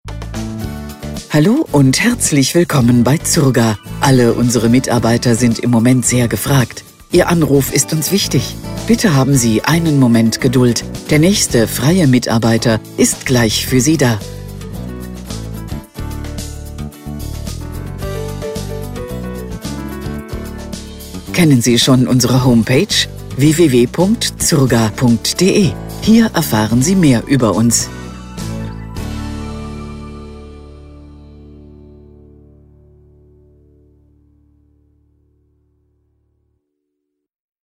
Telefonansagen mit echten Stimmen – keine KI !!!
Warteschleife
Zurga-Warteschleife.mp3